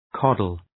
Προφορά
{‘kɒdəl}